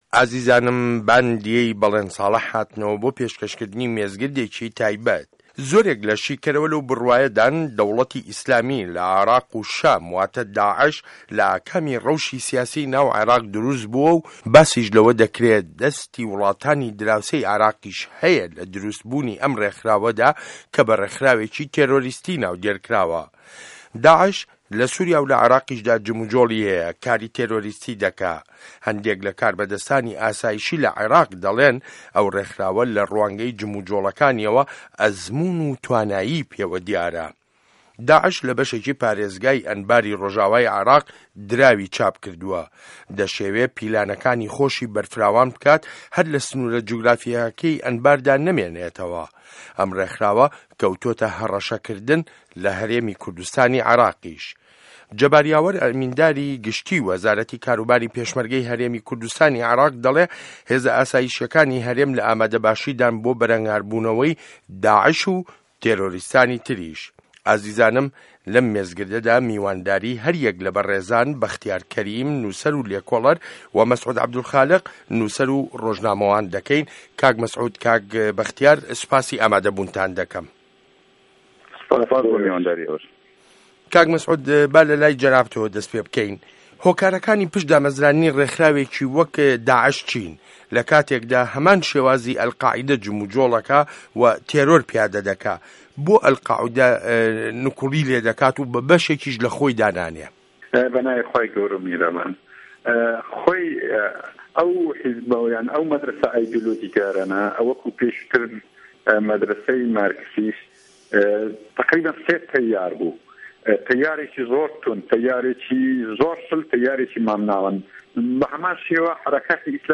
مێزگرد: داعش هه‌ڕه‌شه‌ ده‌کات